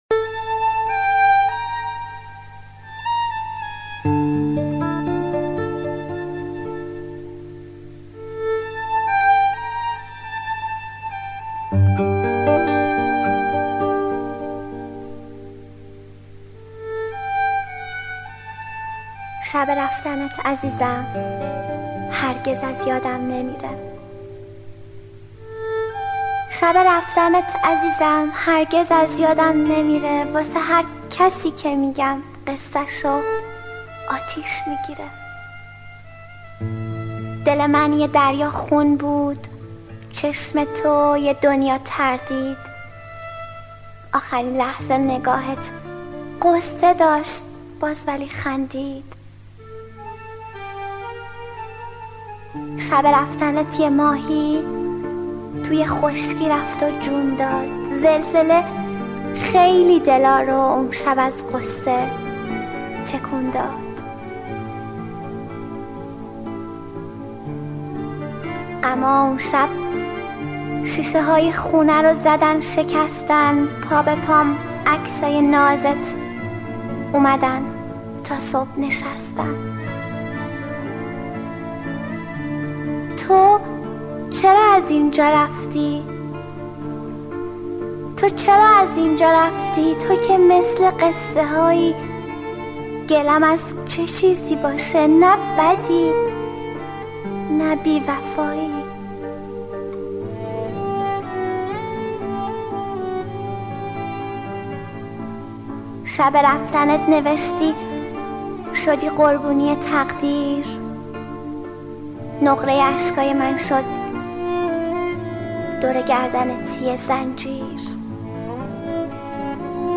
deklame